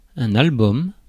Ääntäminen
Ääntäminen Tuntematon aksentti: IPA: /al.bɔm/ Haettu sana löytyi näillä lähdekielillä: ranska Käännös Substantiivit 1. album Suku: m .